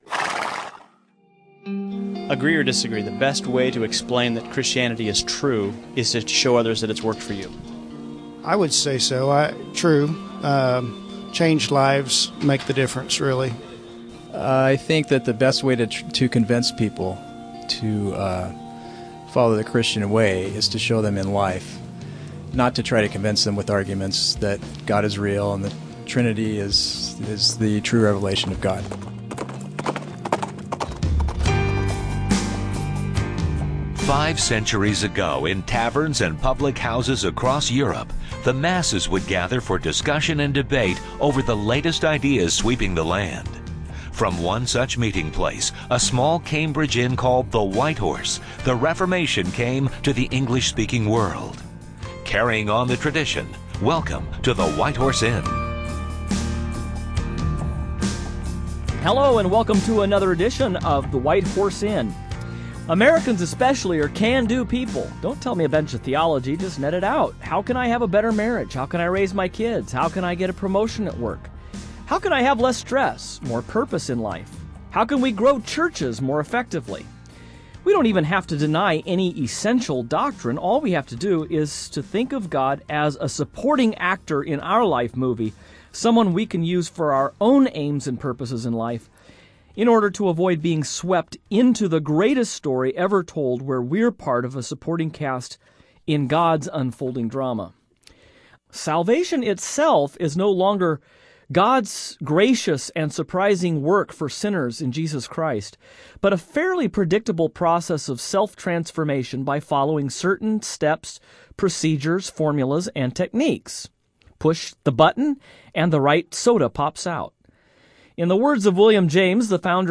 On this edition of the White Horse Inn, the hosts take a look at the philosophy of Pragmatism and its effects on contemporary Christian thought…